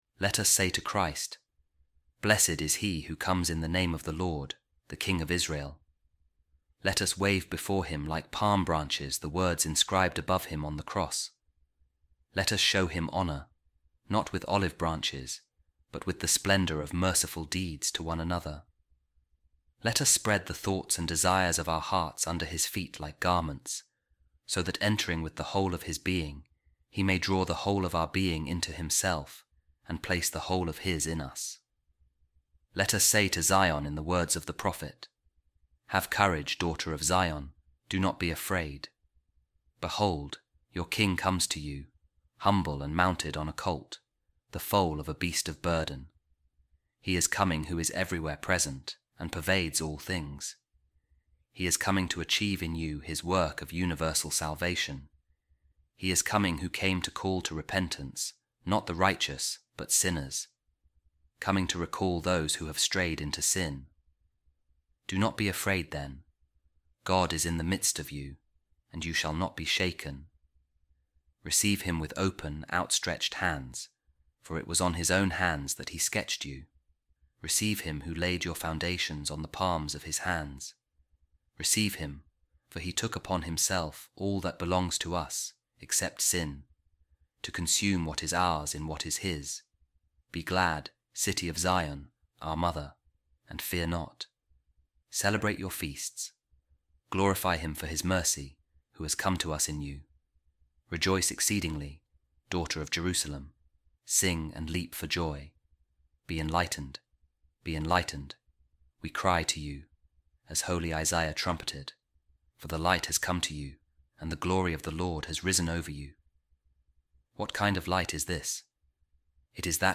A Reading From The Addresses Of Saint Andrew Of Crete | Behold, Your King Is Coming To You, The Holy One, The Saviour